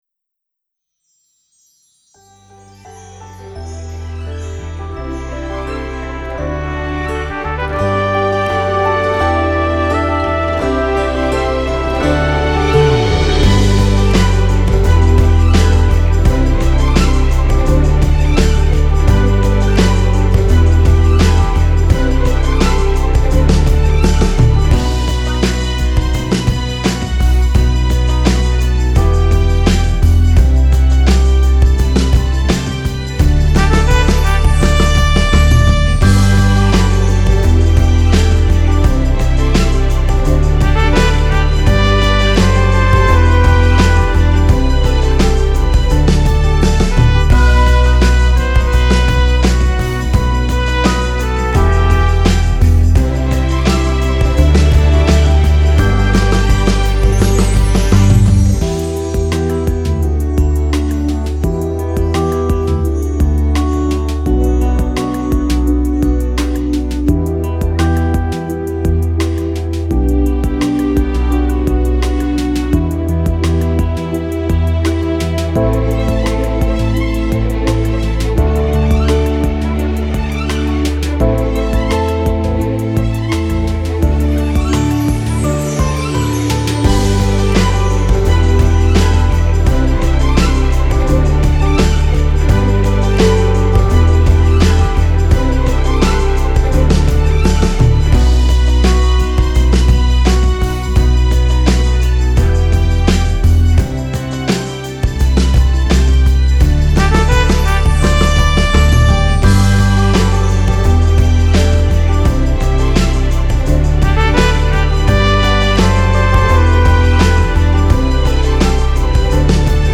BASE STRUMENTALE